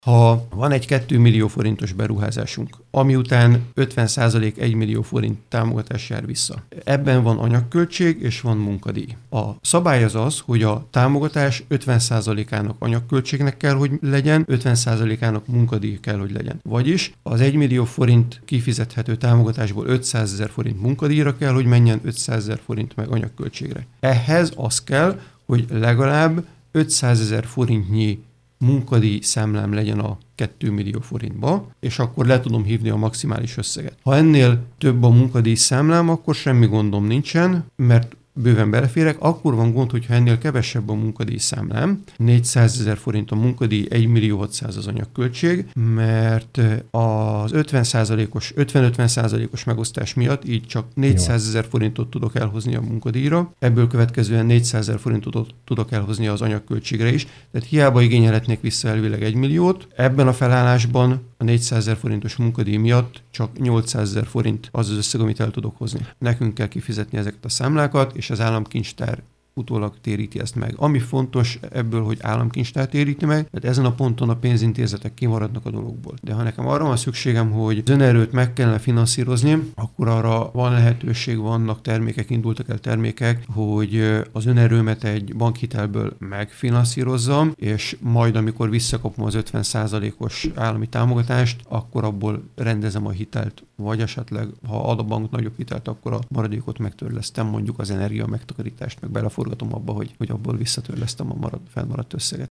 Pogácsás Tibor országgyűlési képviselő, a Belügyminisztérium önkormányzatokért felelős államtitkára arról beszélt, a főváros eddig arányaiban több pénzt kapott, mint Pest megye, azonban most elkezdődik egyfajta kompenzáció.